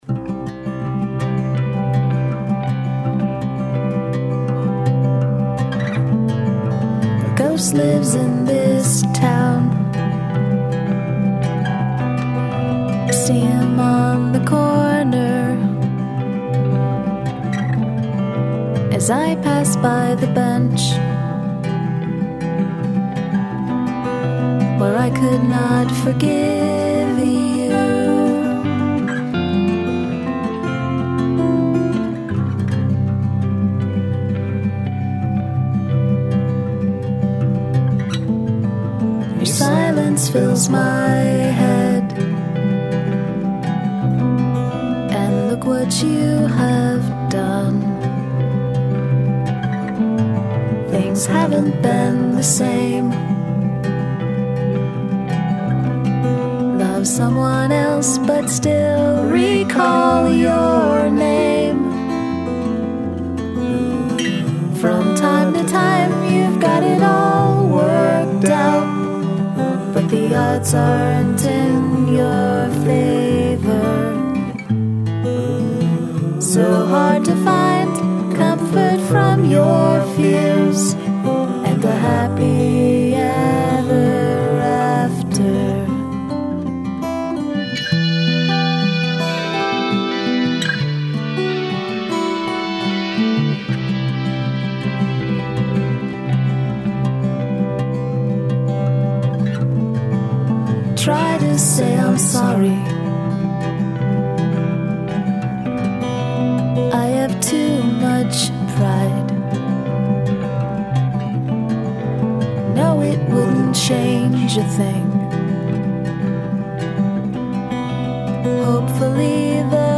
Guitar and Vox only (bass is prohibited)
This totally sounds complete.
The harmonic riffs rule!